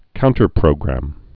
(kountər-prōgrăm, -grəm)